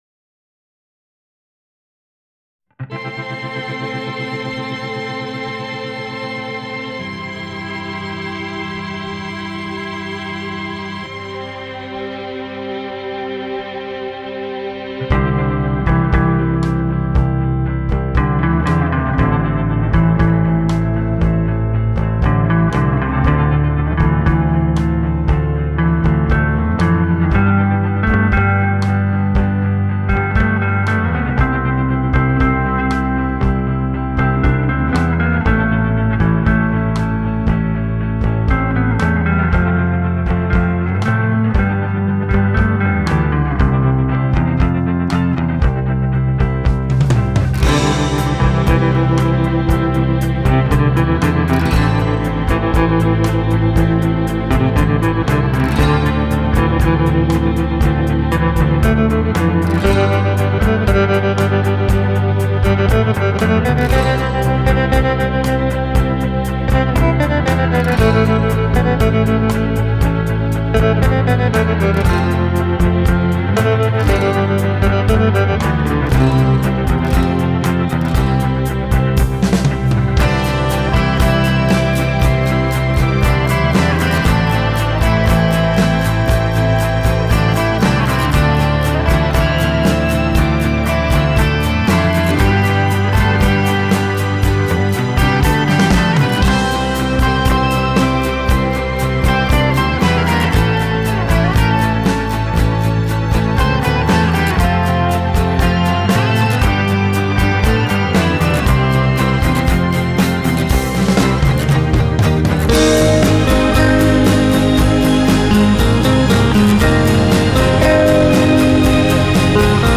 инструментального СЁРФ-РОКА
серф гитариста